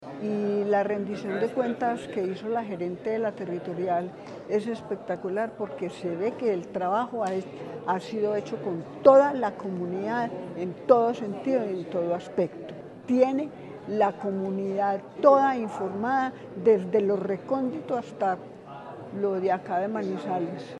La Dirección Territorial de Salud de Caldas (DTSC) llevó a cabo su Audiencia Pública de Rendición de Cuentas correspondiente a la vigencia 2024, un espacio en el que se destacó el trabajo realizado en pro de la salud y el bienestar de los caldenses.